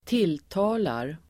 Uttal: [²t'il:ta:lar]